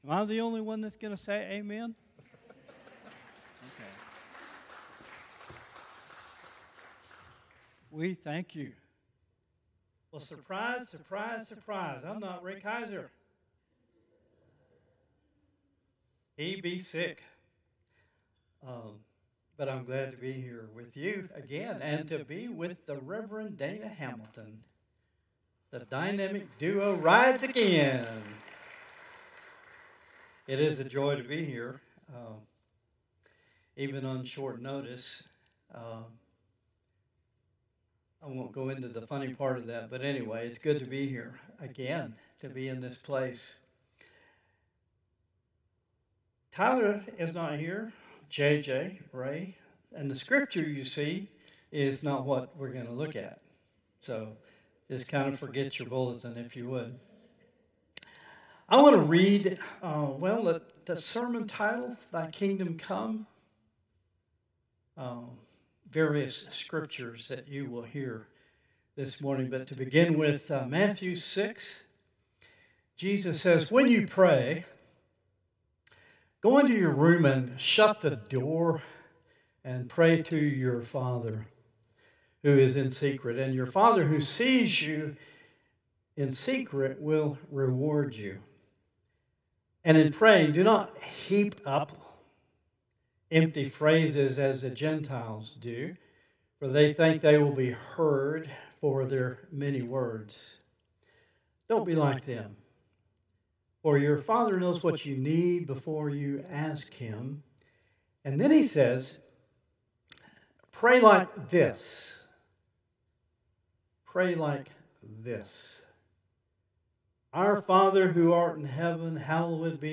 Traditional Service 7/13/2025